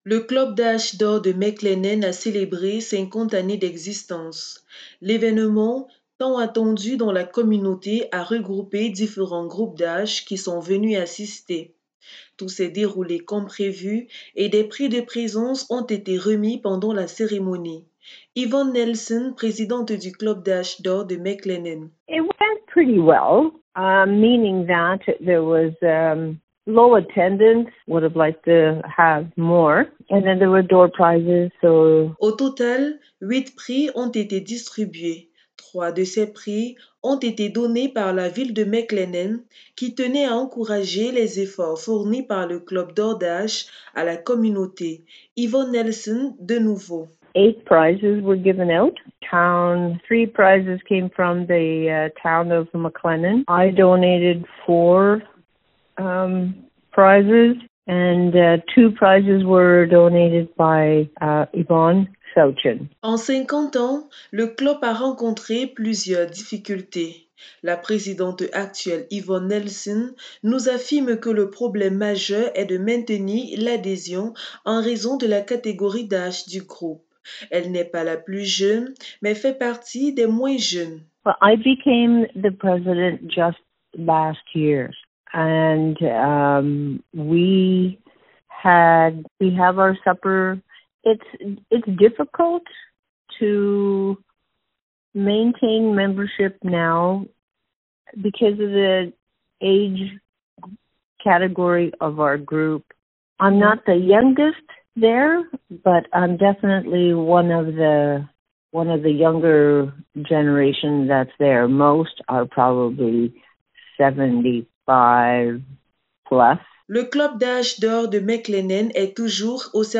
Les détails dans ce reportage :